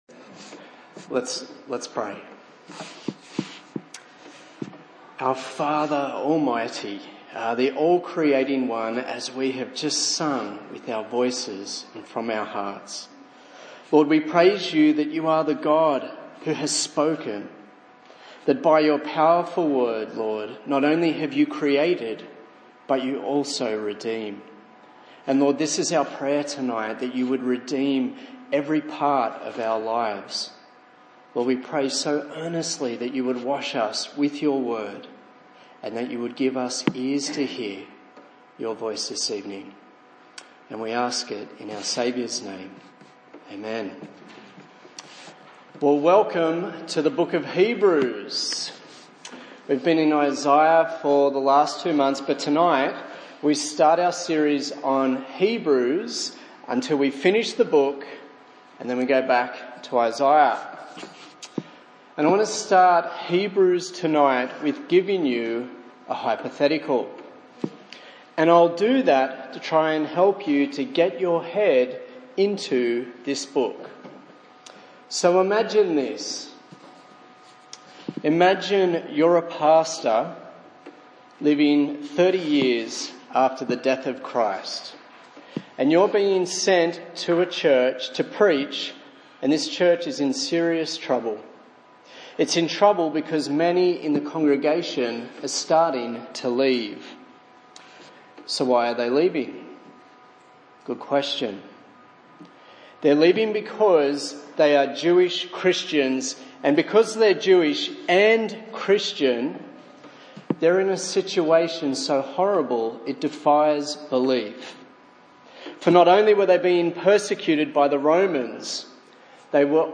A sermon in the series on Hebrews